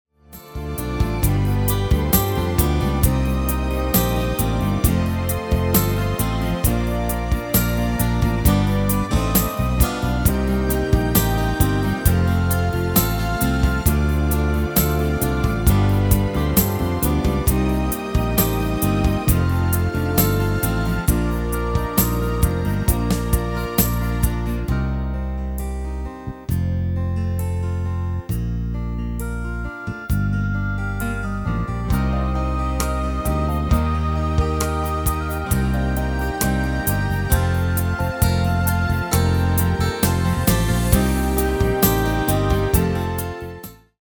Demo/Koop midifile
Genre: Ballads & Romantisch
Toonsoort: Bb
- Vocal harmony tracks